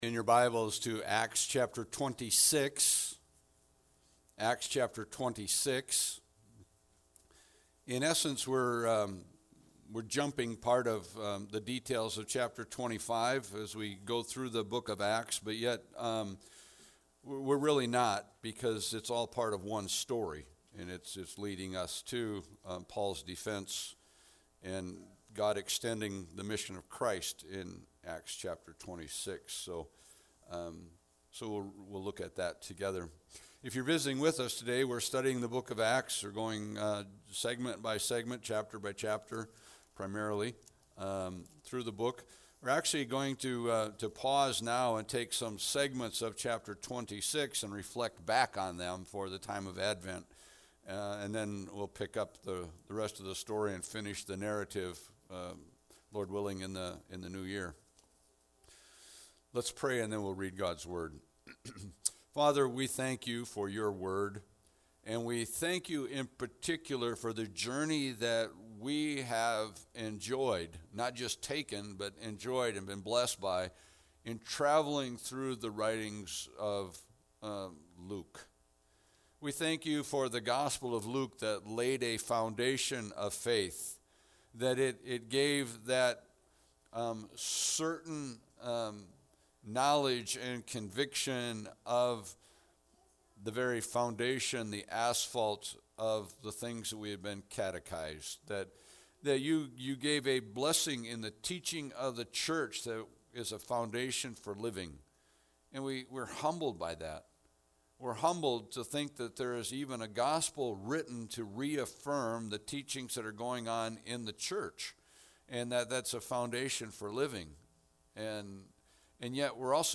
Passage: Acts 26 Service Type: Sunday Service